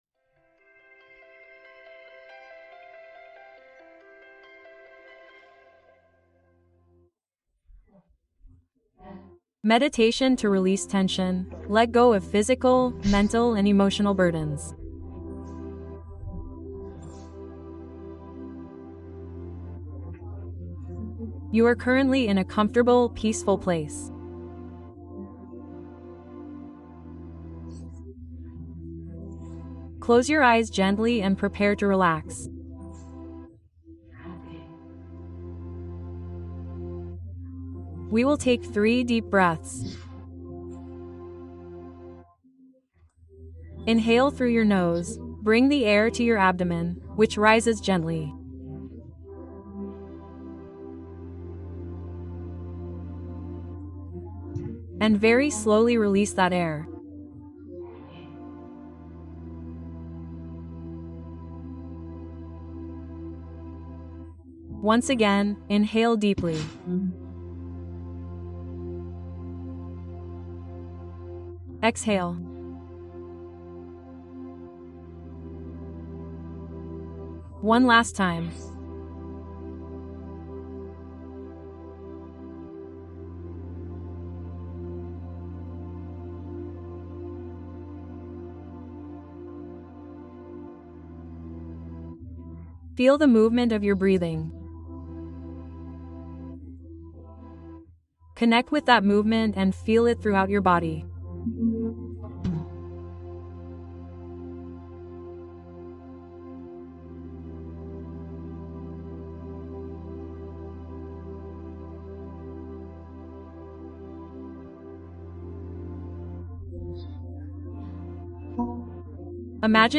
Soltar la Tensión Profunda del Cuerpo: Meditación de Relajación Guiada